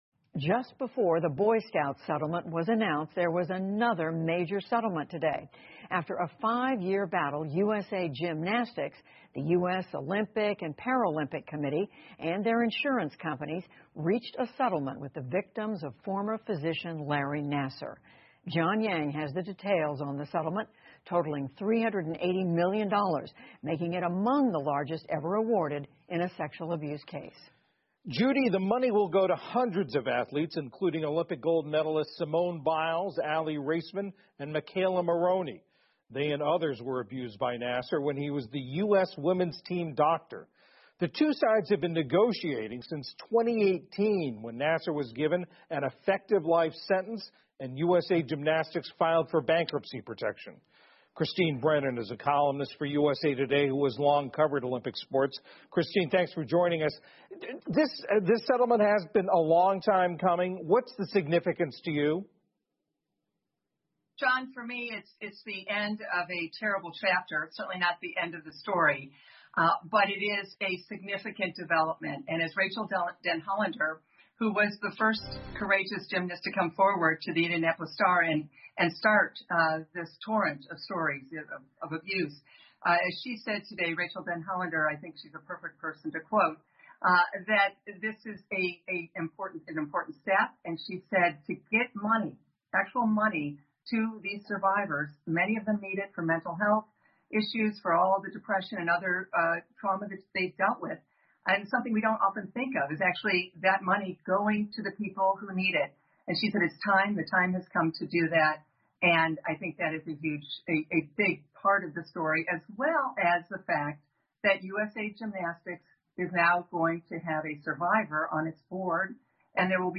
PBS高端访谈:美国体操运动员性虐待诉讼获赔3.8亿美元 听力文件下载—在线英语听力室